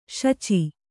♪ śaci